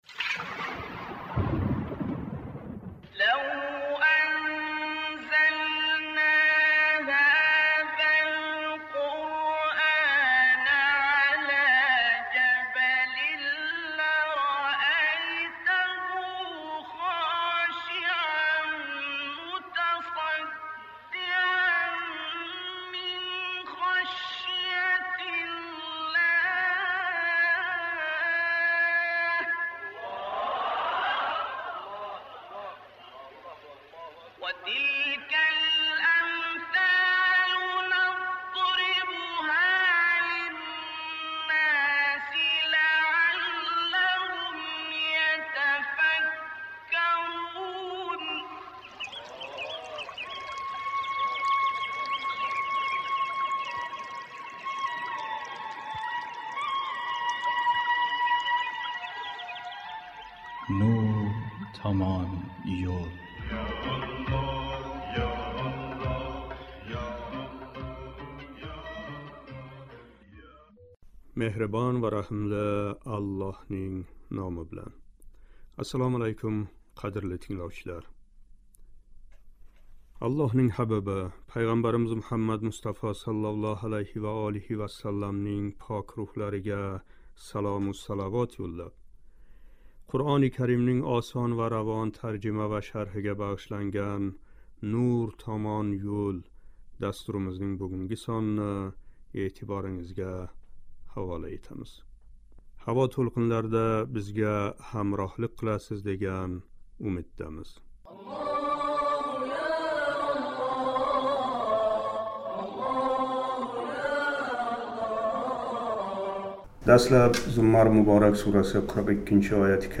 «Зуммар" муборак сураси 42-45-ояти карималарининг шарҳи. Дастлаб “Зуммар” муборак сураси 42- ояти каримаcининг тиловатига қулоқ тутамиз: